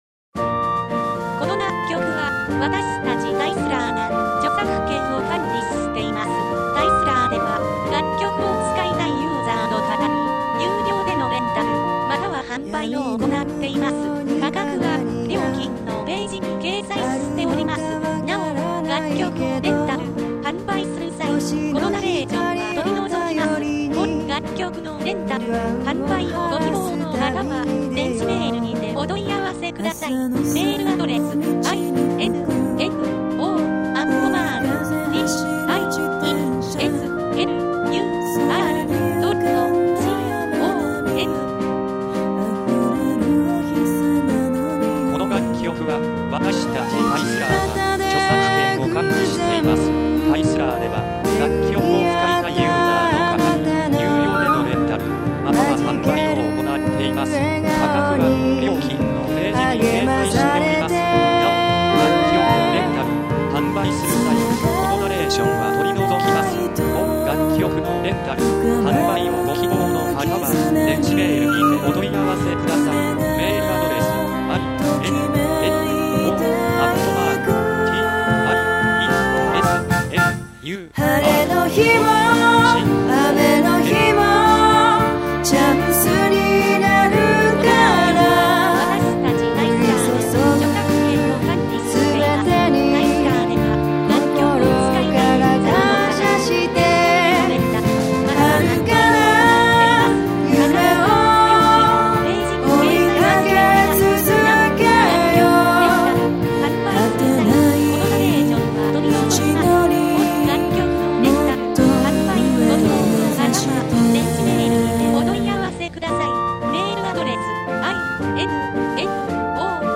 ◆アップテンポ系ボーカル曲